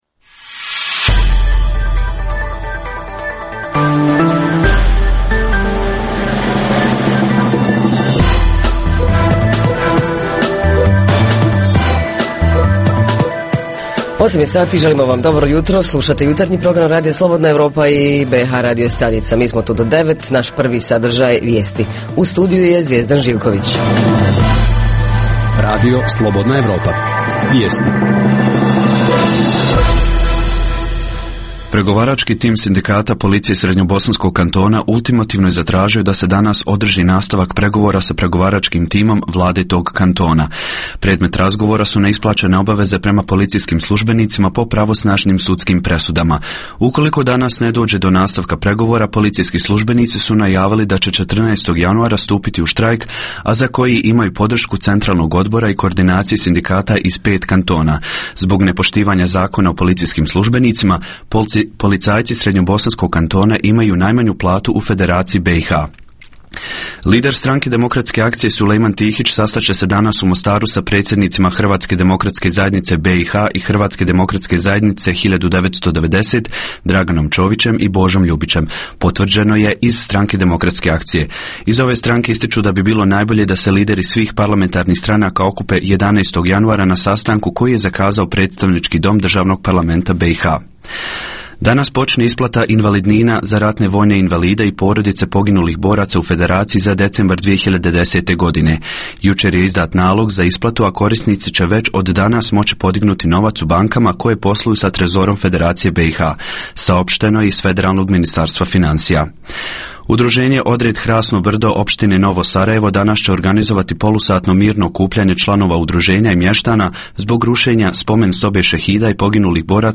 Tema jutarnjeg programa su odnosi između škole (osnovna škola) i porodice. Reporteri iz cijele BiH javljaju o najaktuelnijim događajima u njihovim sredinama.
Redovni sadržaji jutarnjeg programa za BiH su i vijesti i muzika.